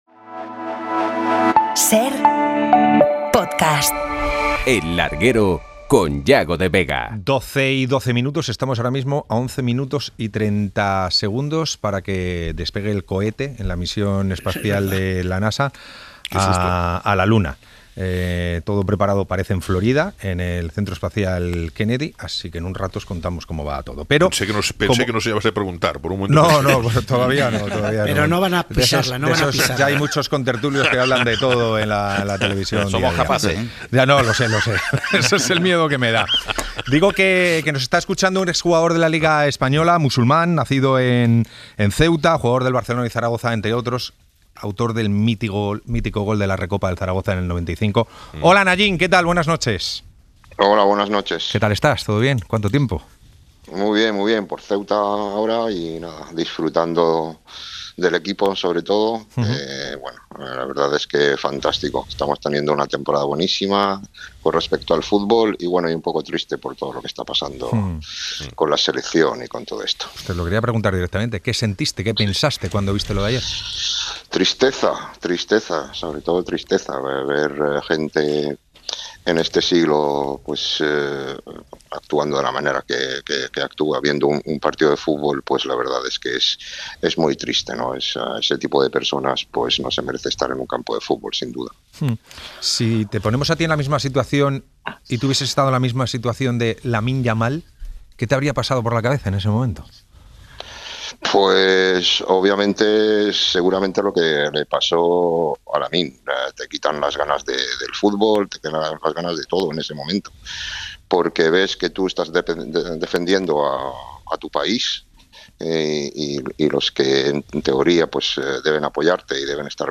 La versión de la RFEF sobre la actuación en Cornellà, la entrevista a Nayim y el despegue de la misión Artemins II 47:31 SER Podcast La RFEF traslada su versión sobre su actuación en Cornellà, hablamos con el exjugador Nayim y vivimos en directo la misión del Artemis II en el regreso de la humanidad a la Luna.